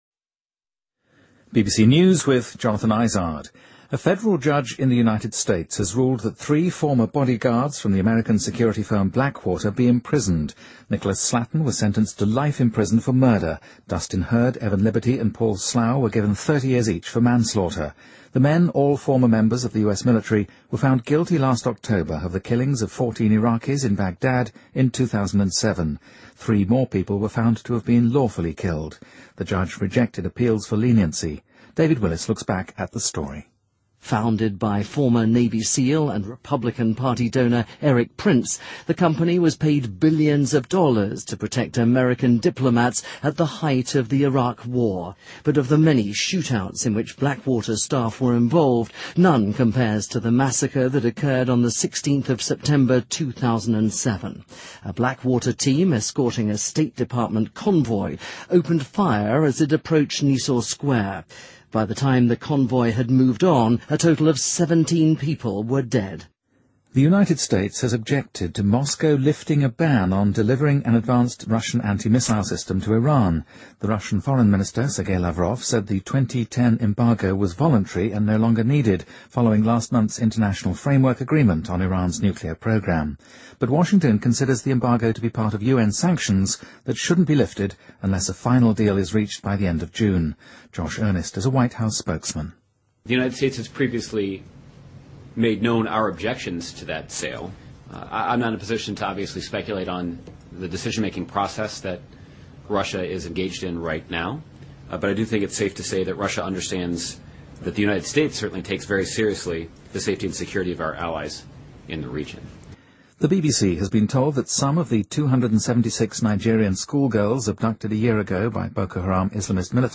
BBC news,美国联邦法院对三名美国黑水保安公司前雇员判处监禁